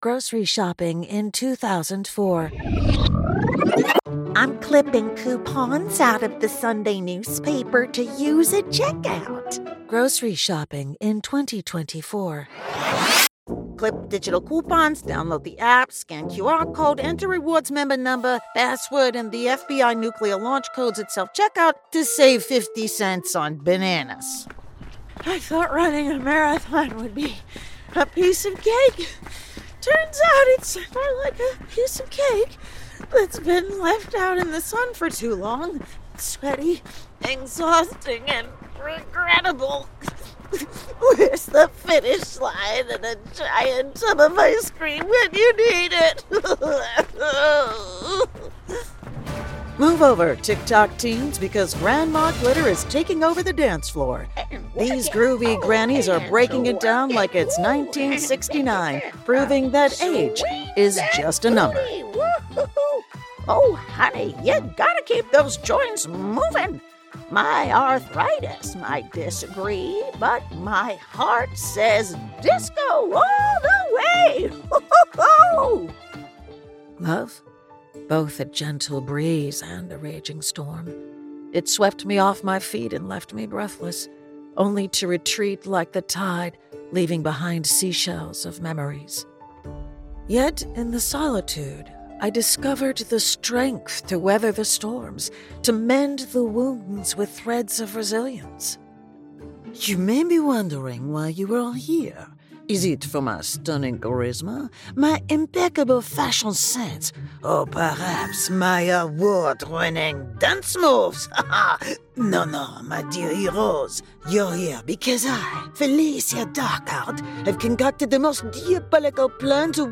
Voice Actor
Warm and engaging, voicing the Mom Next Door, your favorite Granny, or the Creature Under the Stairs.
Studio specs: Double-walled treated ISO booth, Sennheiser MK 4 mic, Mogami Gold cable, Yamaha preamp, Reaper, Izotope 7, external mac mini.